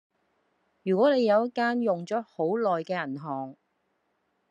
Голоса - Гонконгский 282